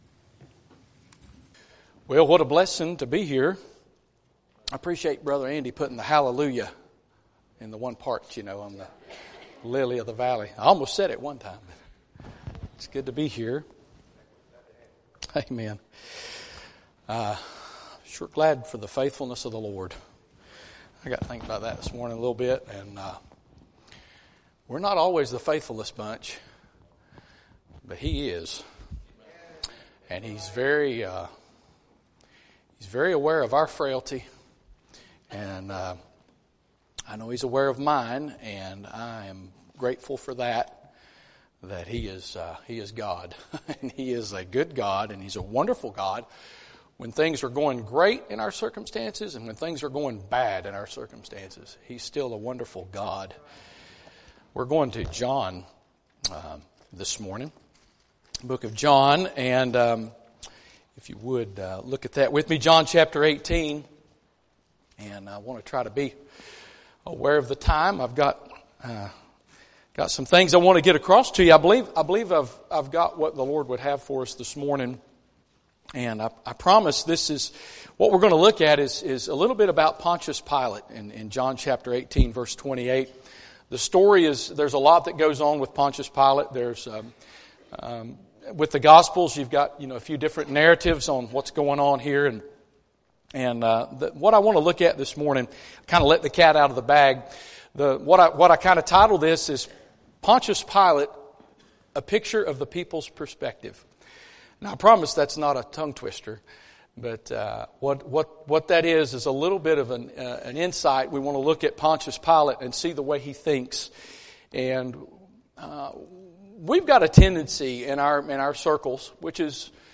2026 Revival Meeting